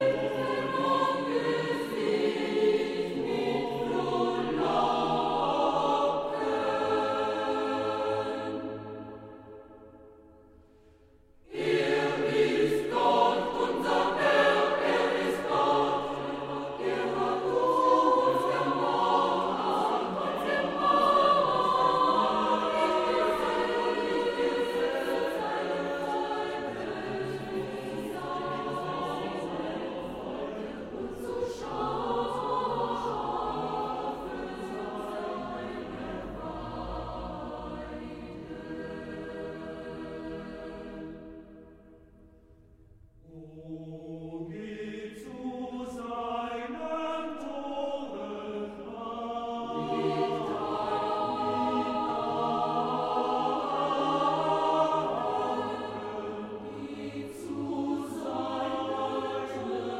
• Sachgebiet: Klassik: Chormusik